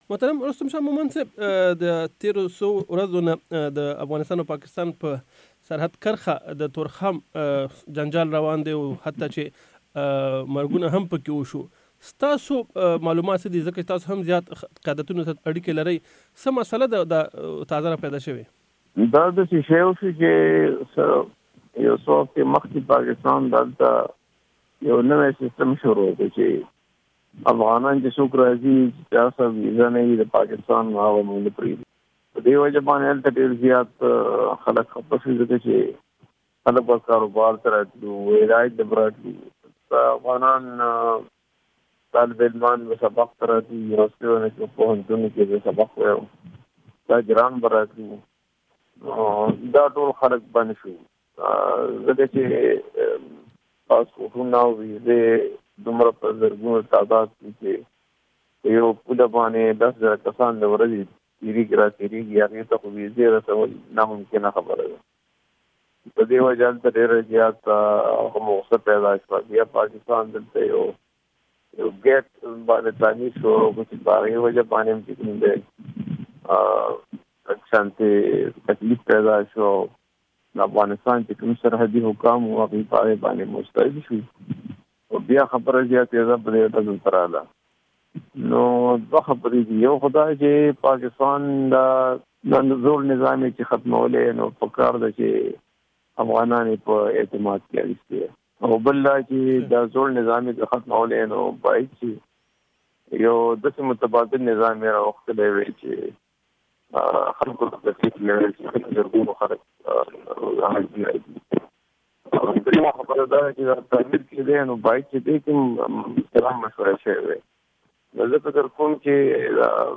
مرکې
د پاکستان د پخواني دیپلومات رستم شاه مومند سره مرکه